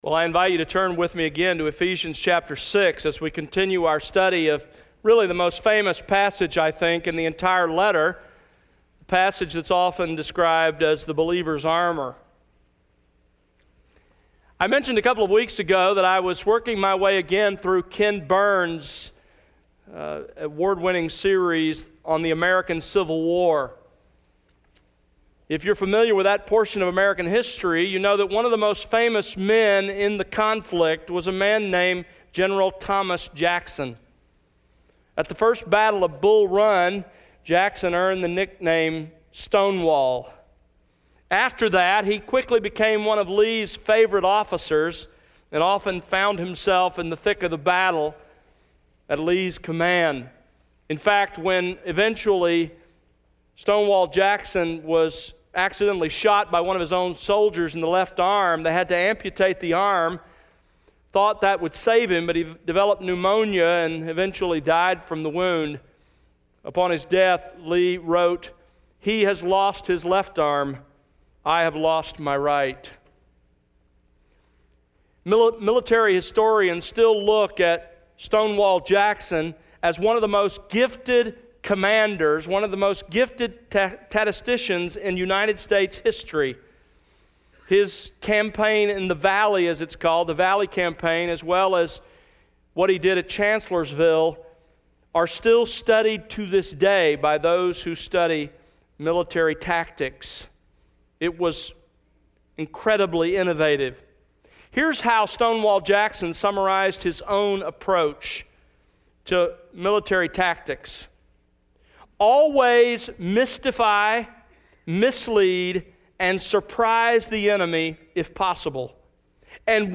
Sermons That Exalt Christ